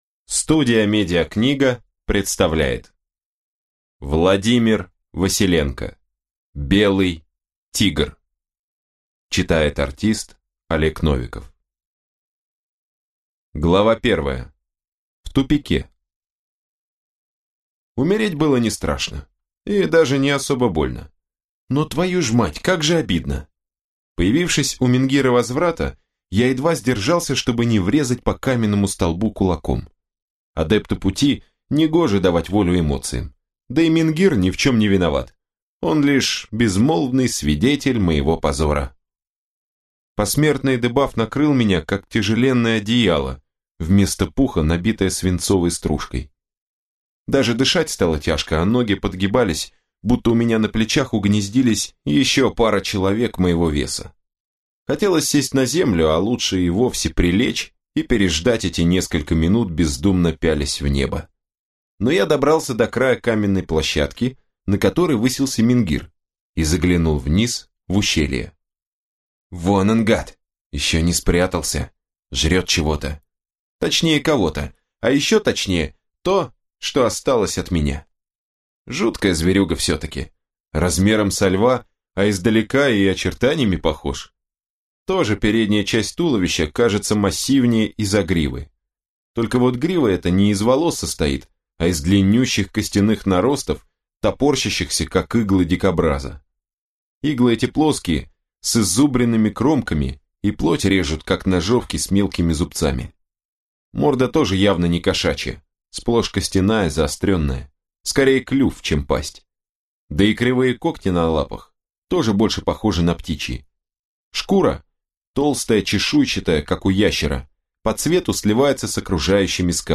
Аудиокнига Белый тигр | Библиотека аудиокниг